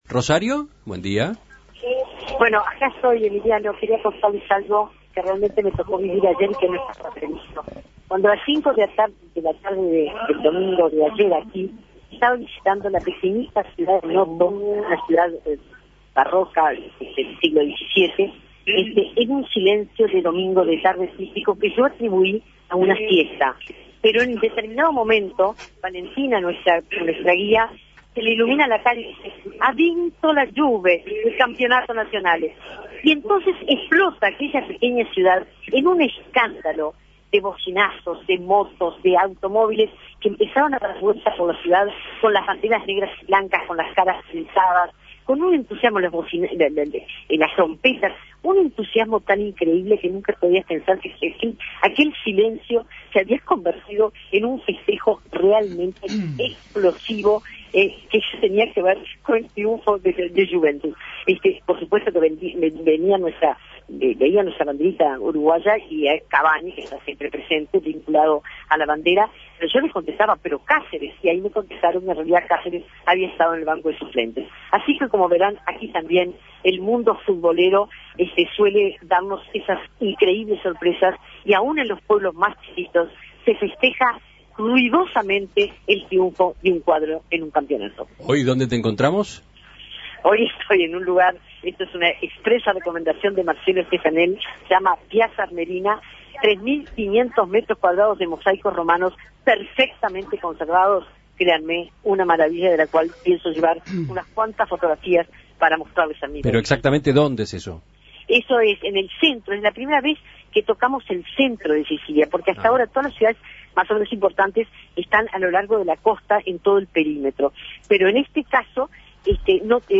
Festejos del título de campeón de Juventus en Piazza Armerina, Italia